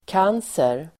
Uttal: [k'an:ser]